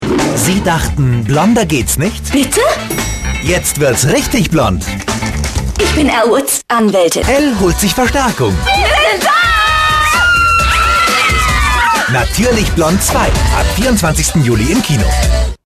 deutscher Sprecher und Schauspieler.
Kein Dialekt
Sprechprobe: Sonstiges (Muttersprache):
voice over talent german